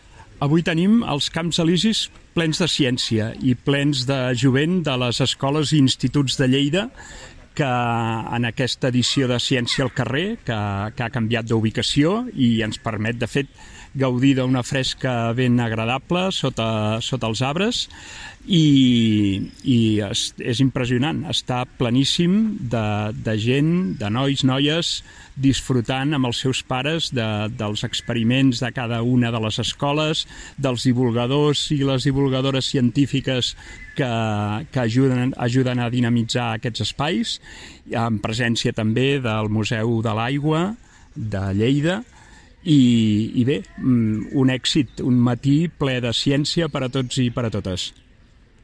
Tall de veu J. Rutllant
tall-de-veu-jaume-rutllant